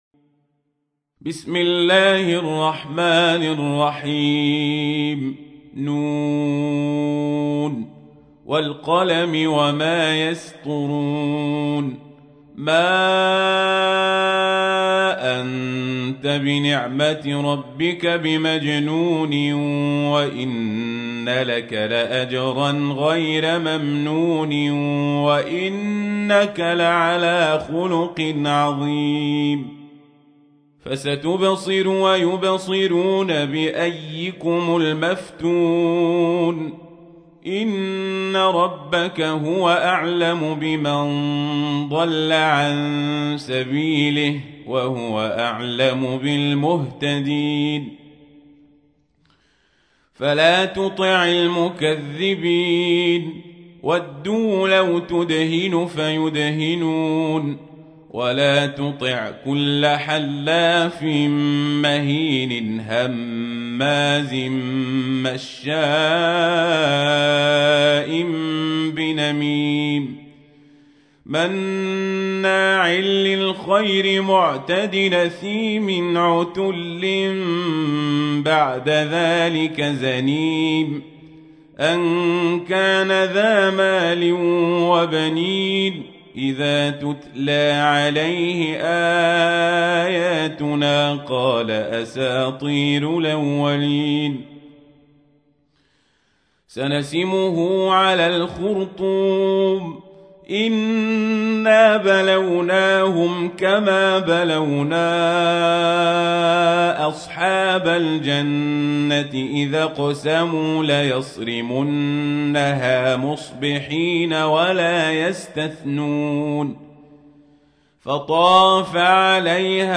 تحميل : 68. سورة القلم / القارئ القزابري / القرآن الكريم / موقع يا حسين